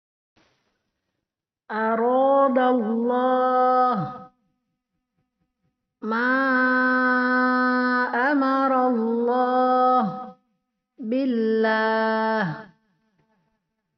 Dapat dibaca dengan 3 cara yaitu:
a. Tebal
b. Tipis